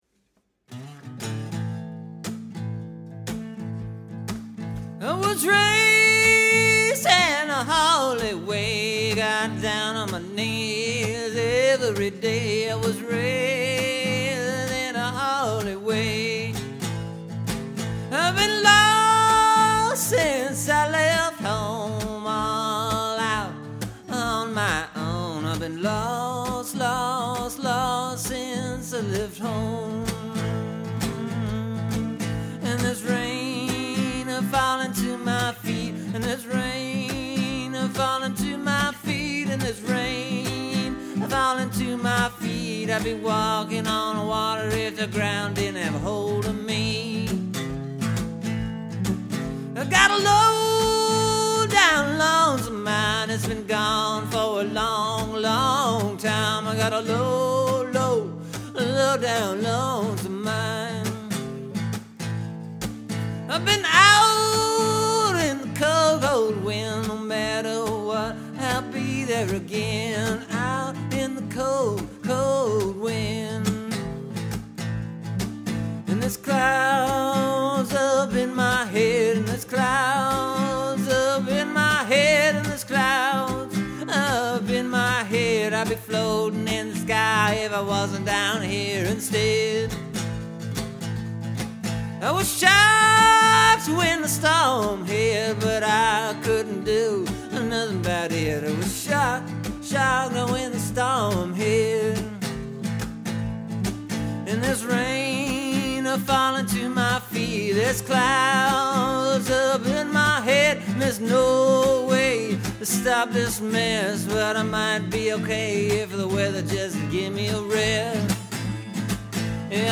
Kinda moves in the right way, I think.
Tried it slow…tried it fast…settled on this mid-tempo version.